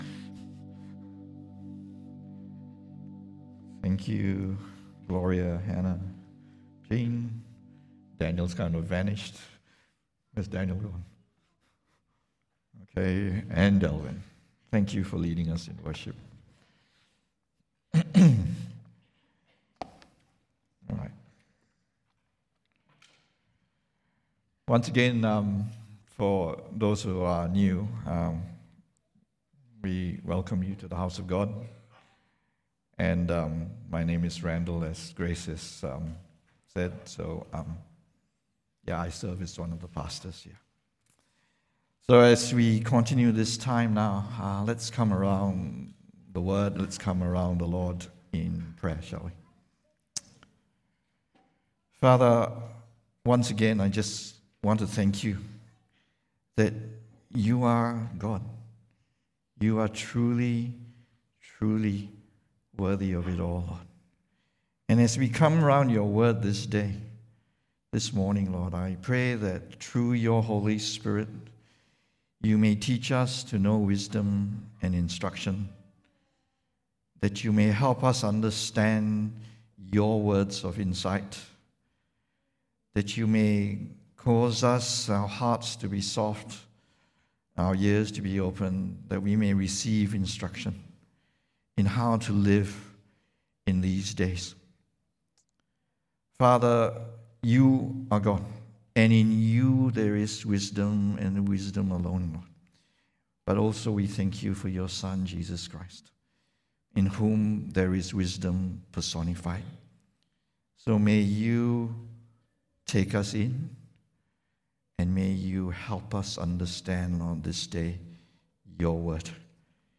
English Worship Service - 2nd July 2023
Sermon Notes